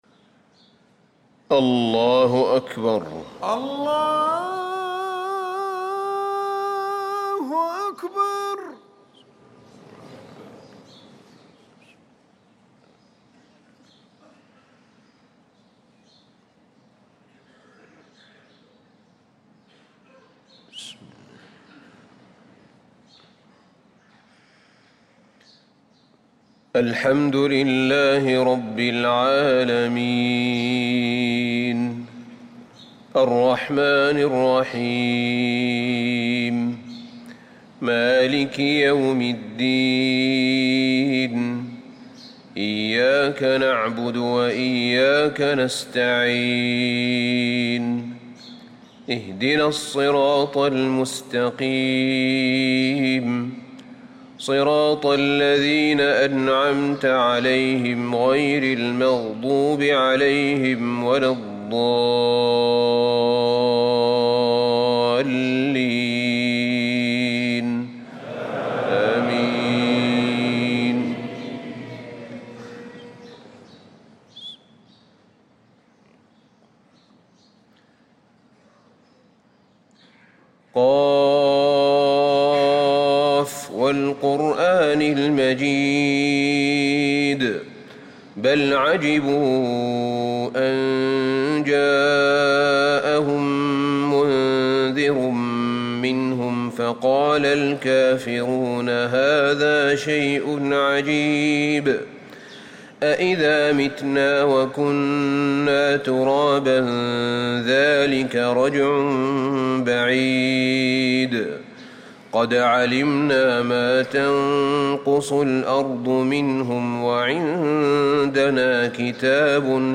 صلاة الفجر للقارئ أحمد بن طالب حميد 18 ربيع الآخر 1445 هـ
تِلَاوَات الْحَرَمَيْن .